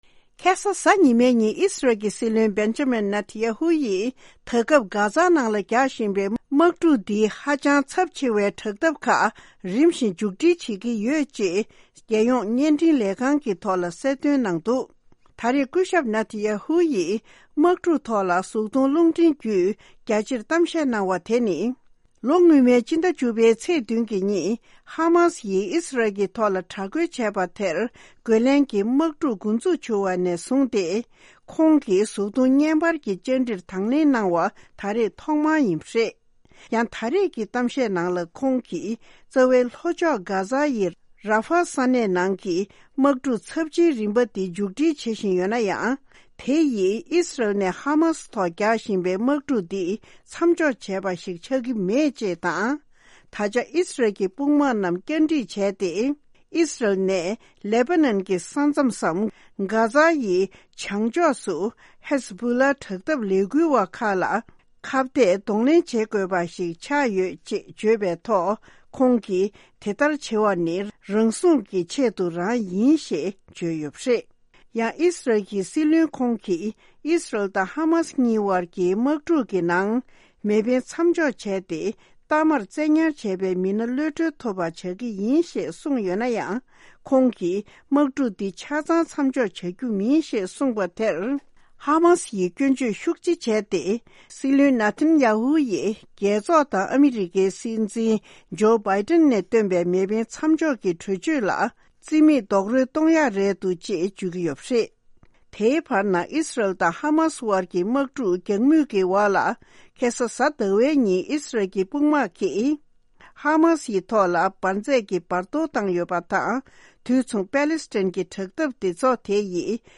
སྙན་སྒྲོན།